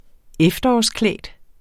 Udtale [ -ˌklεˀd ]